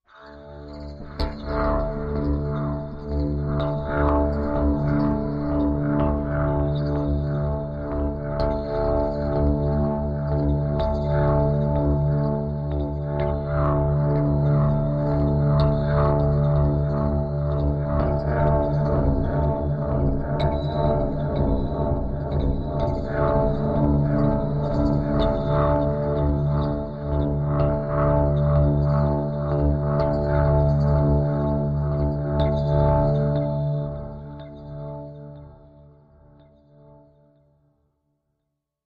Side Winder Low Electric Pulses Echo with High Tones